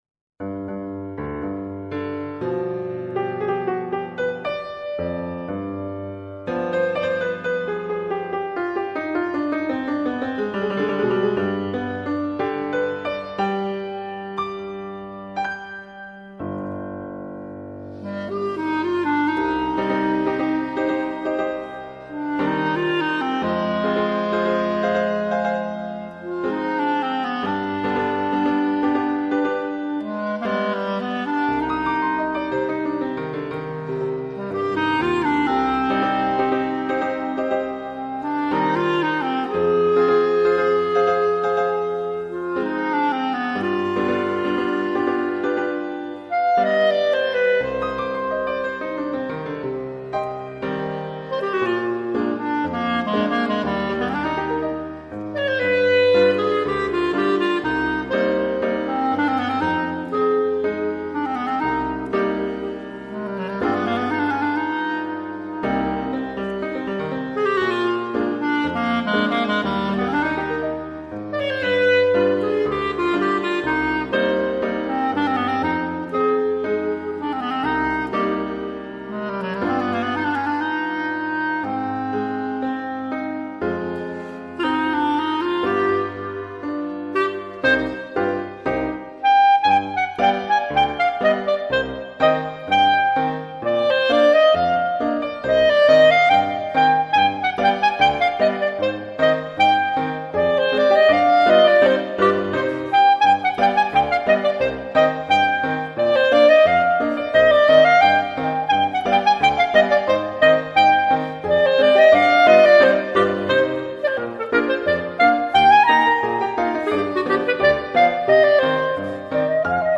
Czardas per clarinetto in Sib e pianoforte